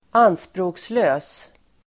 Uttal: [²'an:språ:kslö:s]